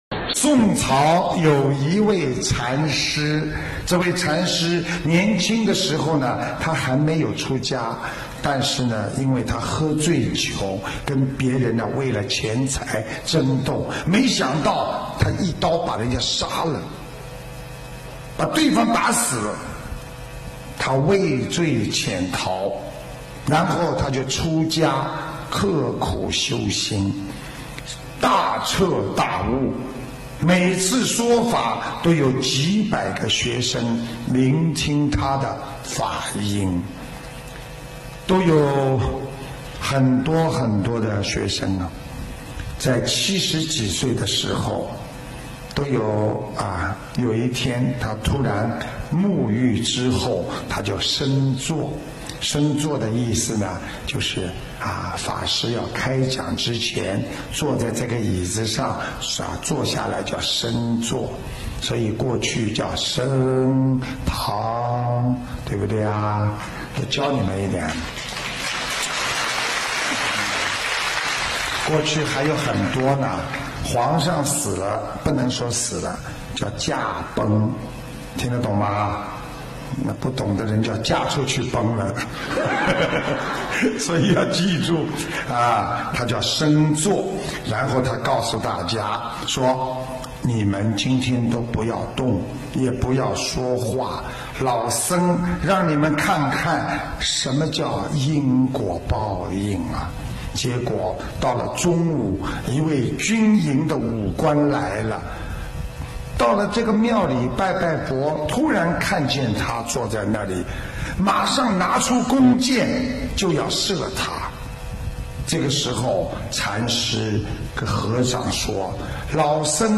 音频：因果报应，谁都逃不了·师父讲小故事大道理